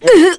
Morrah-Vox_Damage_kr_01.wav